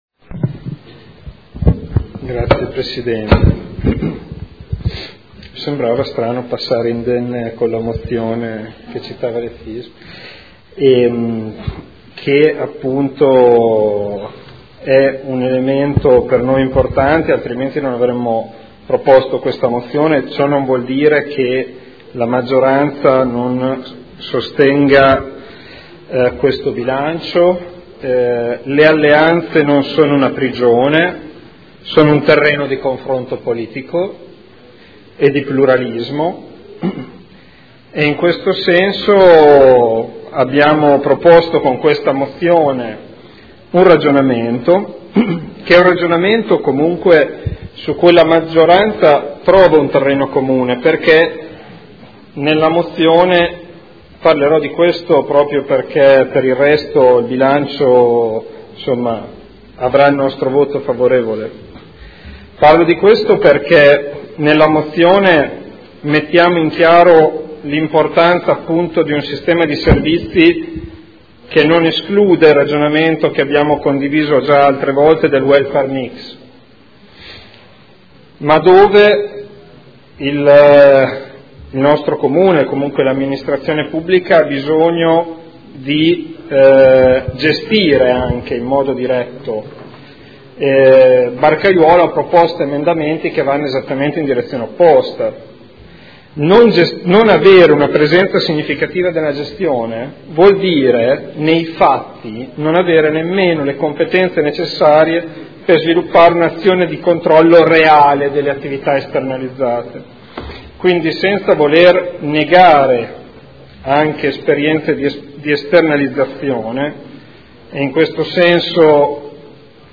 Seduta del 13 marzo. Dichiarazioni di voto sulle delibere accessorie e sul bilancio